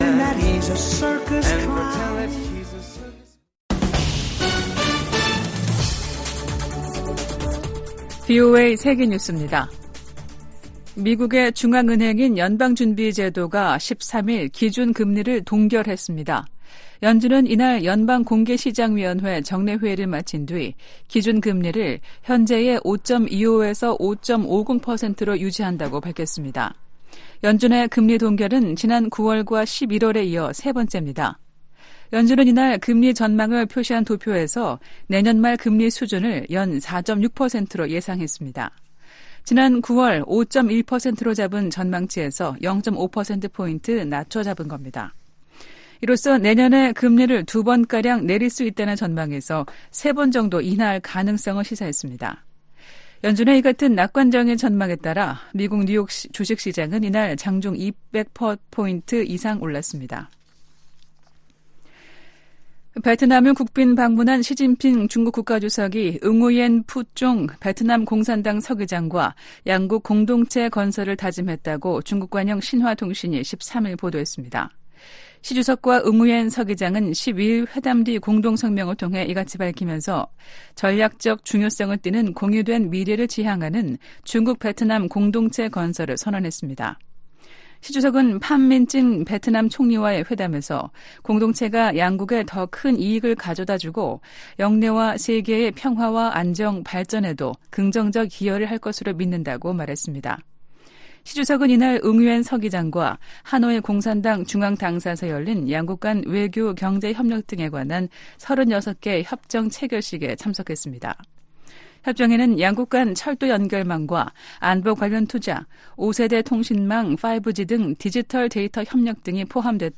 VOA 한국어 아침 뉴스 프로그램 '워싱턴 뉴스 광장' 2023년 12월 14일 방송입니다. 미국 재무부가 러시아 기업에 반도체 기술을 제공한 한국인을 제재했습니다. 미국이 북한 위협에 대응해 구축한 미사일 방어체계를 이용해 중거리탄도미사일을 공중 요격하는 시험에 성공했습니다. 미 국방부는 B-21전폭기 등 신형 핵전력이 선제타격 목적이라는 북한의 주장을 일축했습니다.